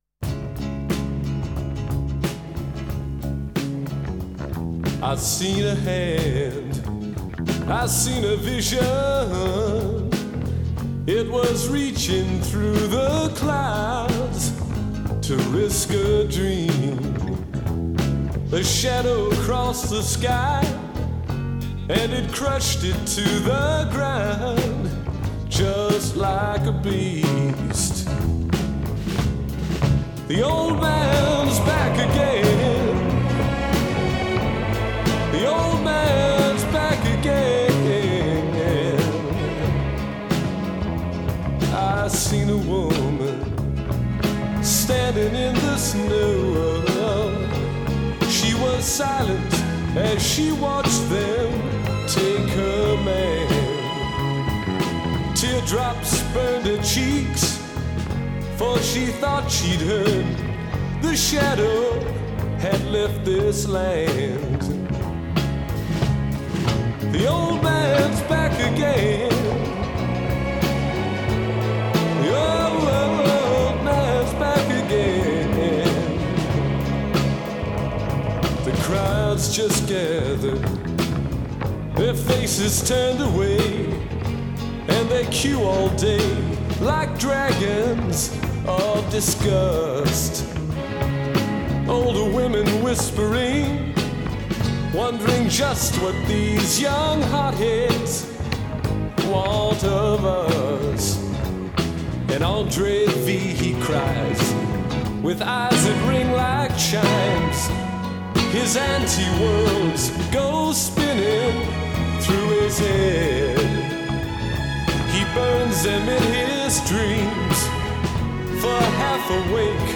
It’s the phrasing.